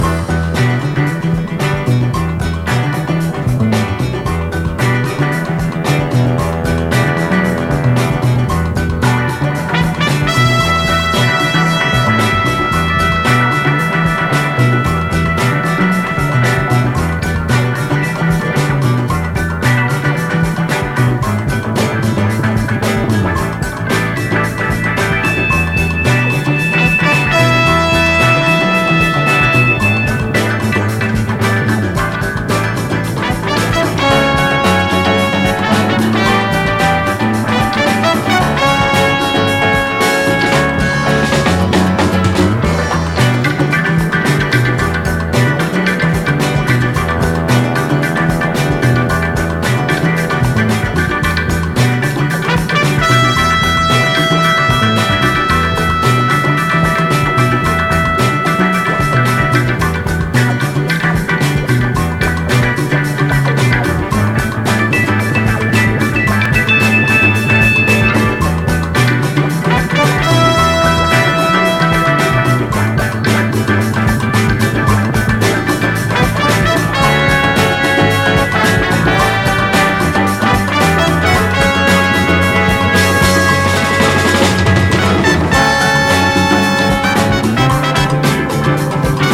ROCK / 70'S
サイケデリックで幻想的に美しい